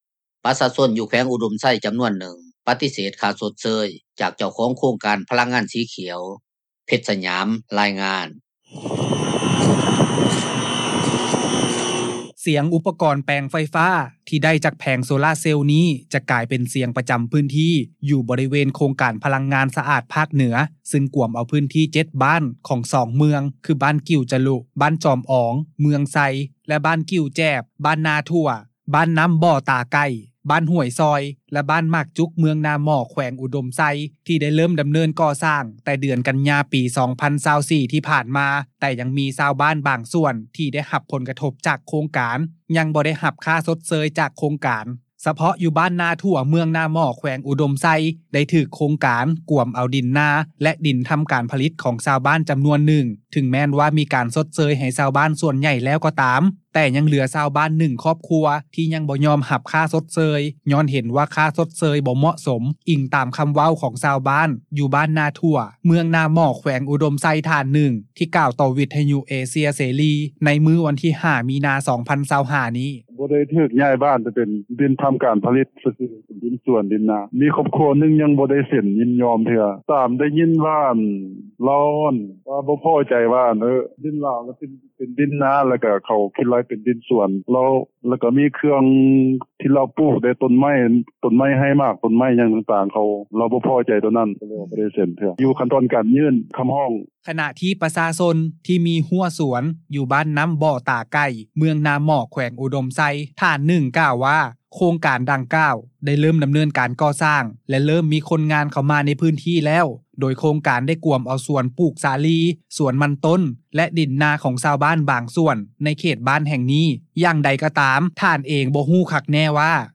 ສຽງອຸປະກອນແປງໄຟຟ້າ ທີ່ໄດ້ຈາກແຜງໂຊລ່າເຊນນີ້ ຈະກາຍເປັນສຽງປະຈໍາພື້ນທີ່ ຢູ່ບໍລິເວນ ໂຄງການພະລັງງານສະອາດ ພາກເໜືອ ເຊິ່ງກວມພື້ນທີ່ 7 ບ້ານຂອງ 2 ເມືອງ ຄືບ້ານກິ່ວຈະລຸ ບ້ານຈອມອອງ ເມືອງໄຊ ແລະ ບ້ານກີວແຈບ, ບ້ານນາຖົວ, ບ້ານນໍ້າບໍ່ຕາໄກ່, ບ້ານຫ້ວຍຊອຍ ແລະບ້ານໝາກຈຸກ ເມືອງນາໝໍ້ ແຂວງອຸດົມໄຊ ທີ່ໄດ້ເລີ່ມດໍາເນີນການກໍ່ສ້າງ ແຕ່ເດືອນກັນຍາ ປີ 2024 ທີ່ຜ່ານມາ ແຕ່ຍັງມີຊາວບ້ານ ບາງສ່ວນ ທີ່ໄດ້ຮັບຜົນກະທົບ ຈາກໂຄງການ ຍັງບໍ່ໄດ້ຄ່າຊົດເຊີຍຈາກໂຄງການ.